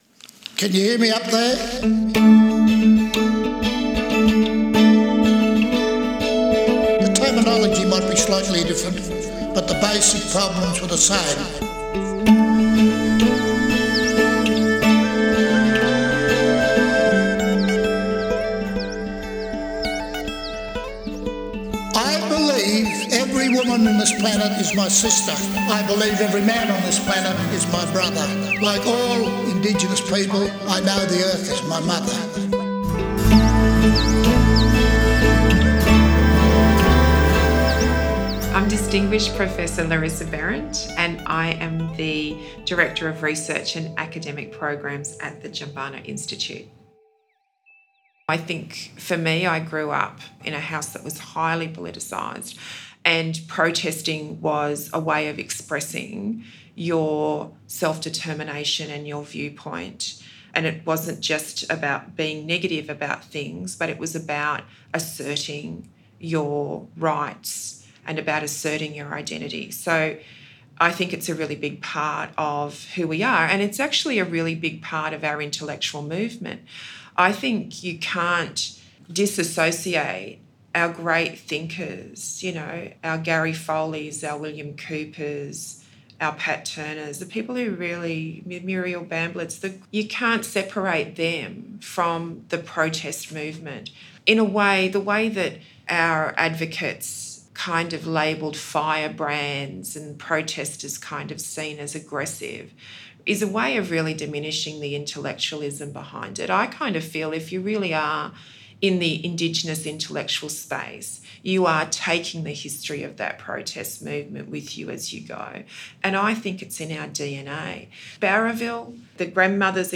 We caught up with Distinguished Professor Larissa Behrendt to talk about the impact of Jumbunna’s research on and for the community. Over two podcasts, this being the first one, she gives us the importance of why Jumbunna has become one of the leading Indigenous Research Units in Australia.